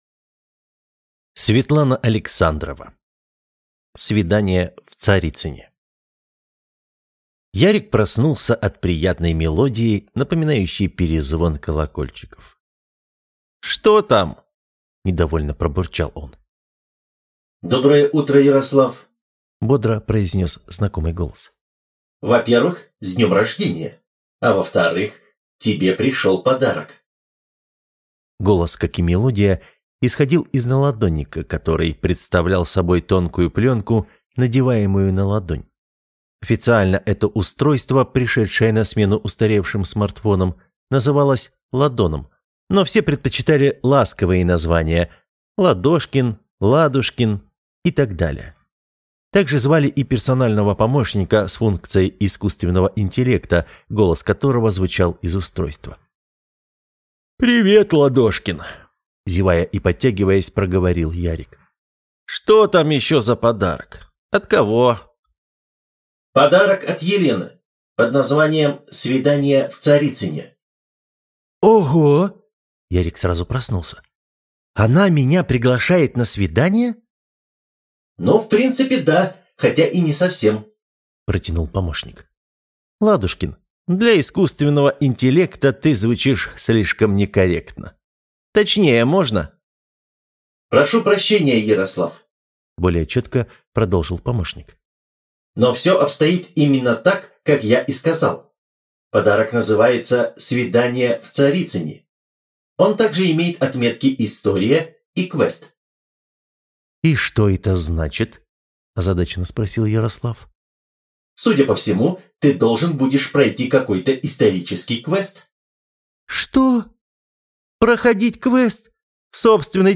Аудиокнига Свидание в Царицыне | Библиотека аудиокниг